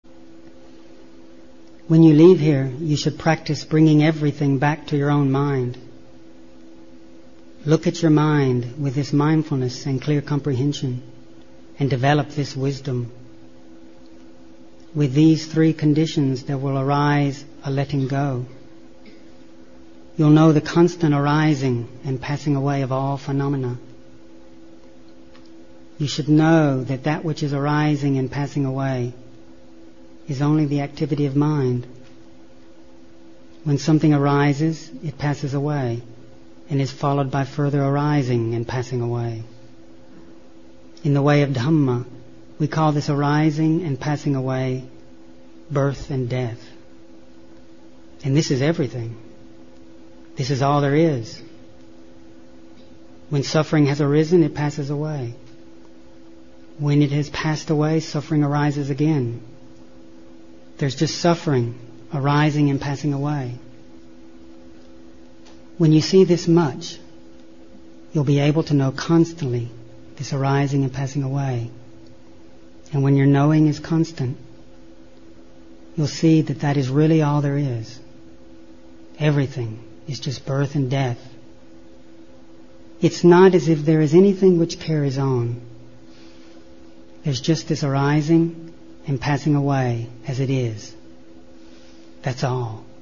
3.2. Quote: “There’s just suffering arising and passing away.” — Ajahn Chah.